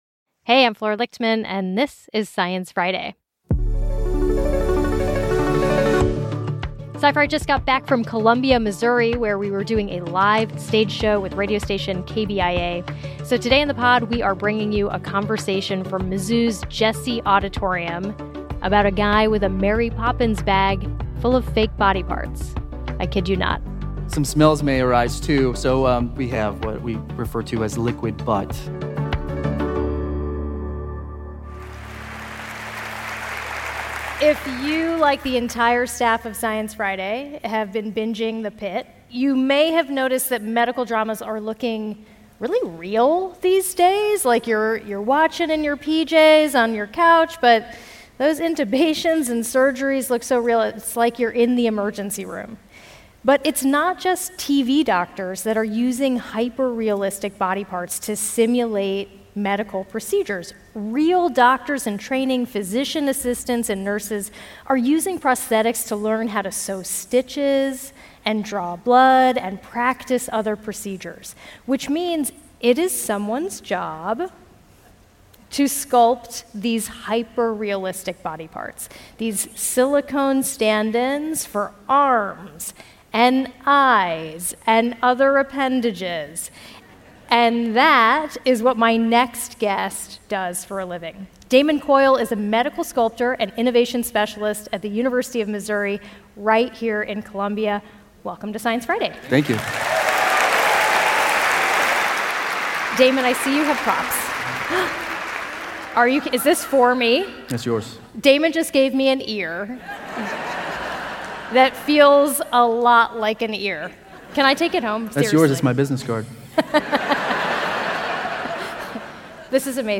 They make things like lifelike arms for practicing blood draws or a set of eyeballs for ocular trauma training. On stage in Columbia, Missouri